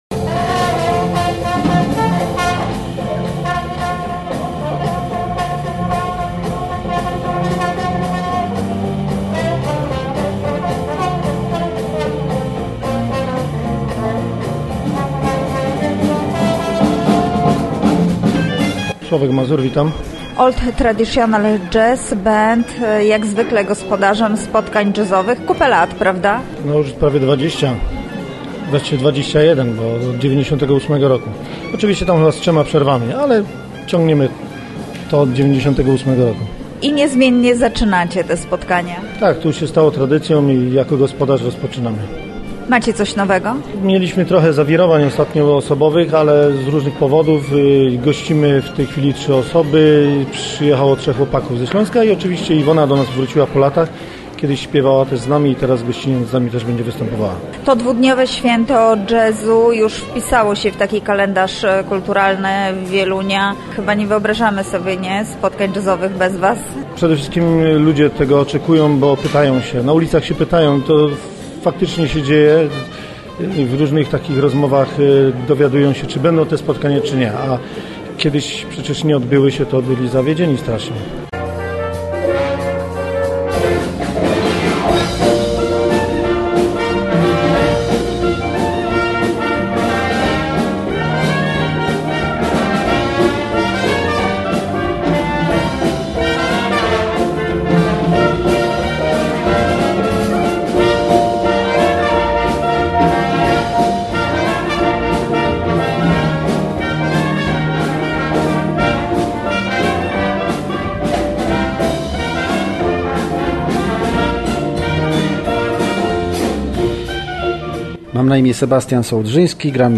Wczoraj koncerty odbyły się w sali widowiskowej Wieluńskiego Domu Kultury.